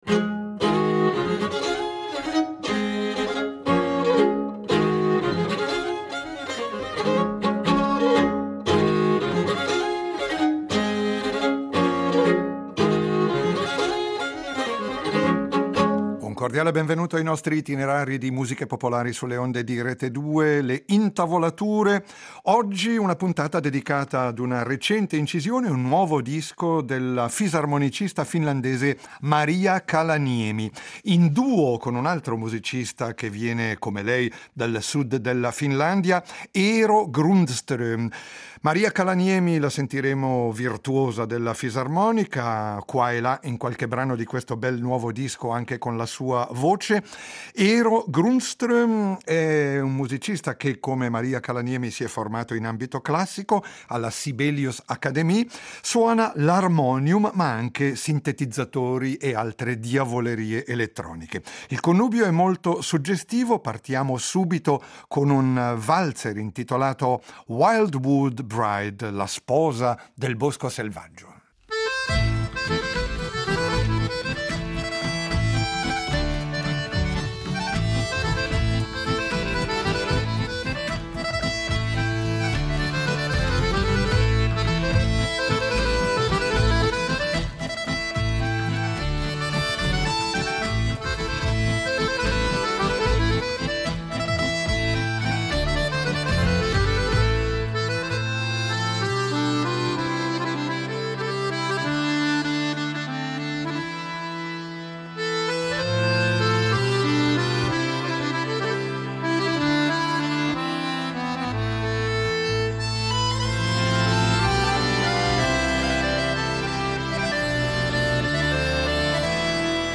nuova musica folk del mondo nordico
harmonium
la fisarmonicista finlandese